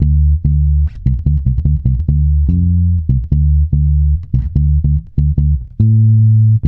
-MM RAGGA D.wav